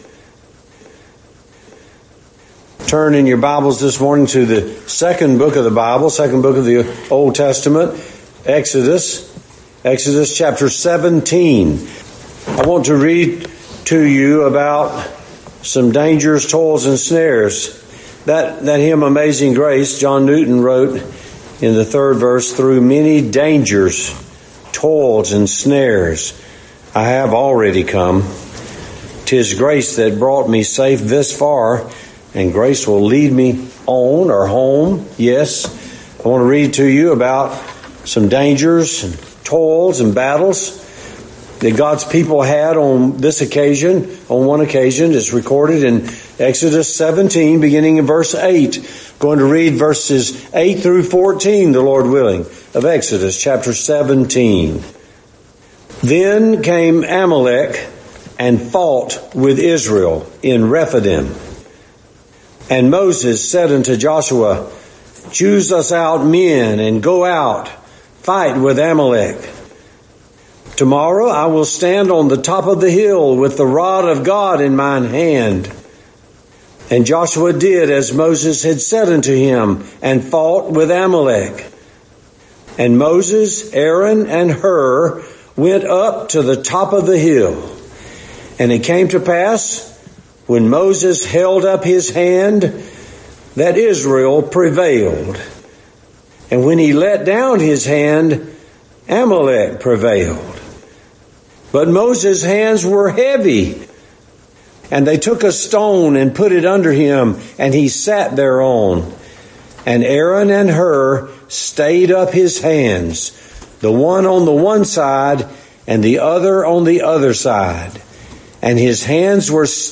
Sermon by Speaker